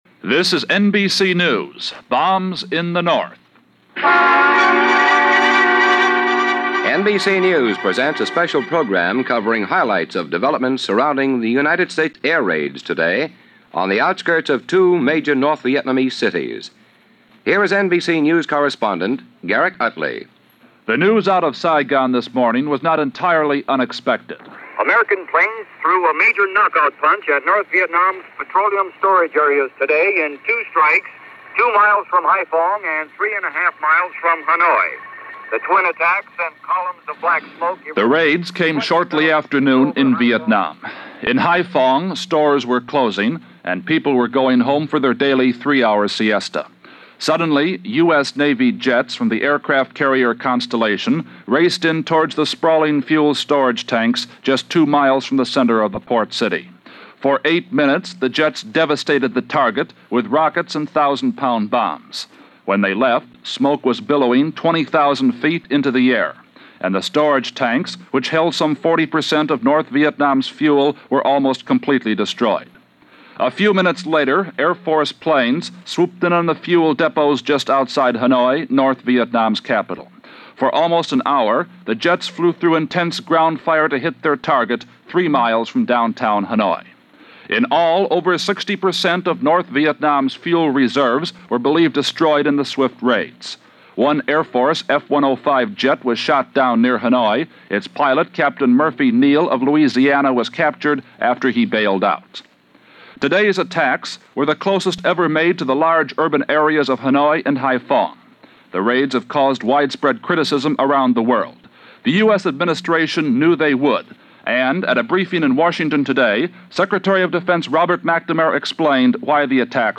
June 29, 1966 - The Vietnam War: Bombing The North - Escalation By Inches - Sec. Of Defense Robert McNamara Press Conference.